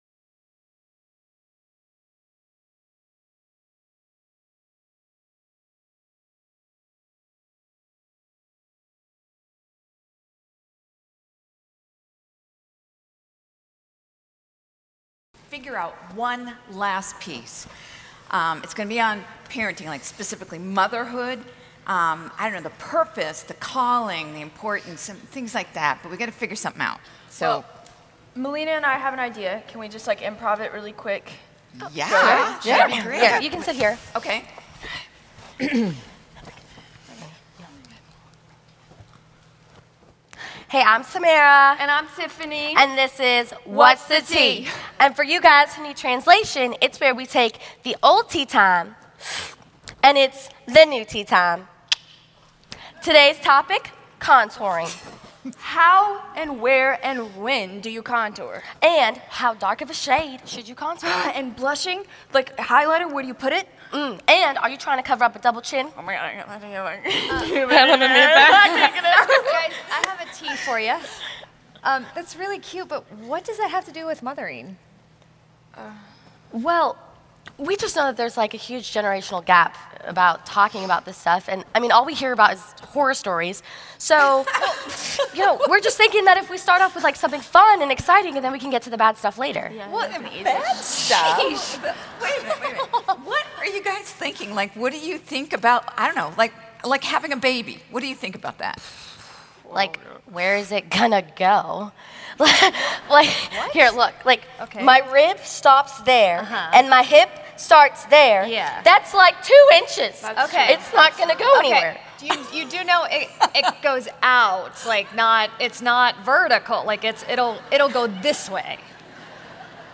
Message 13 (Drama): Motherhood
Revive '17 Adorned Conference - DVD Set